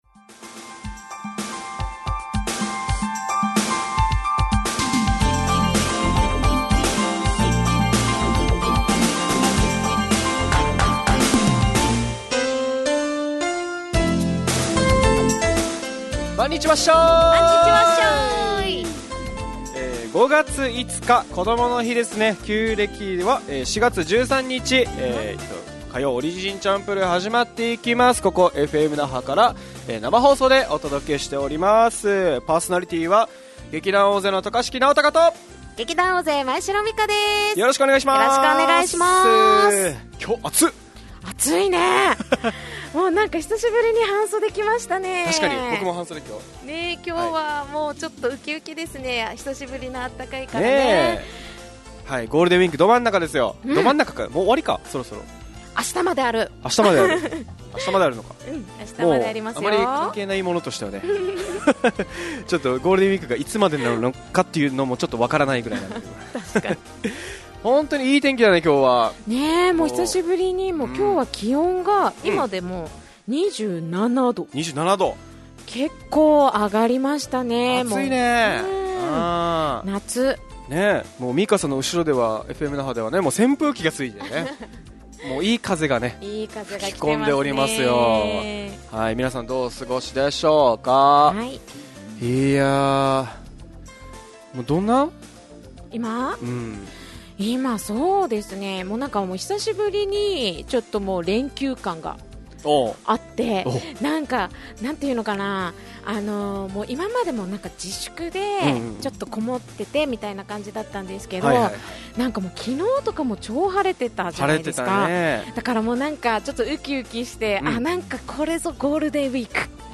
fm那覇がお届けする沖縄のお笑い集団オリジンと劇団O.Z.Eメンバー出演のバラエティ番組